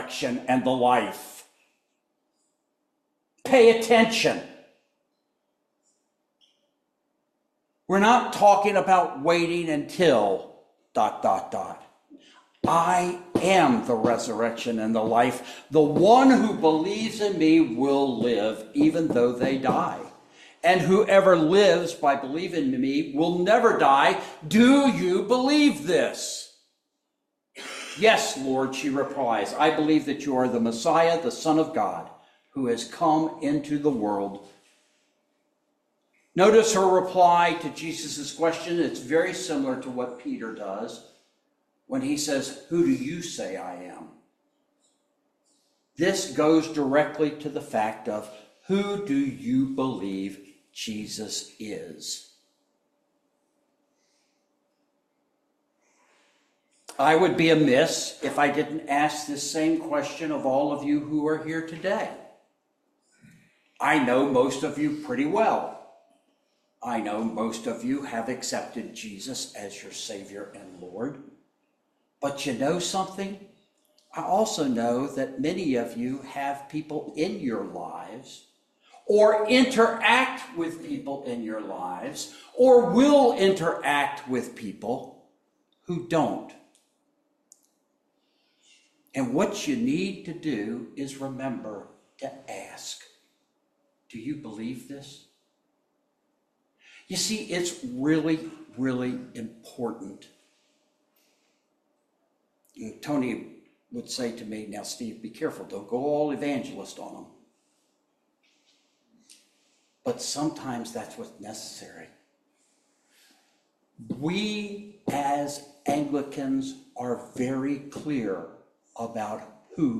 Grace Anglican Church